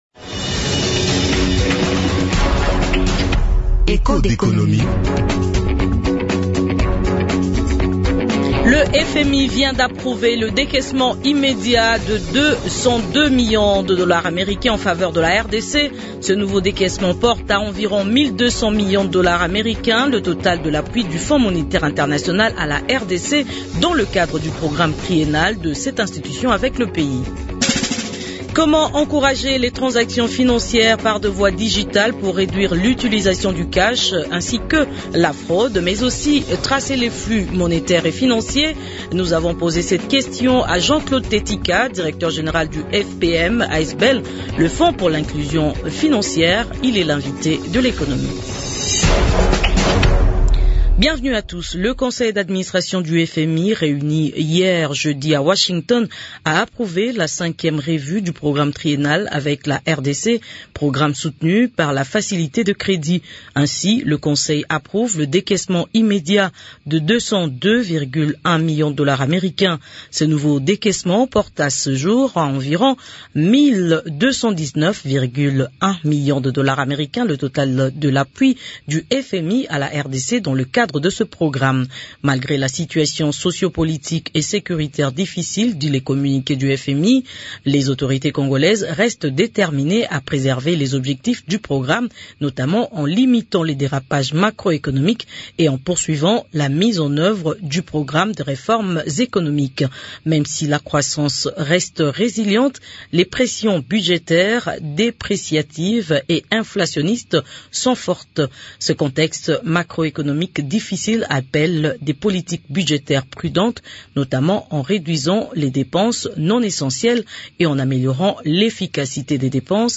Il est l'invité d’Echos d'économie du vendredi 15 décembre 2023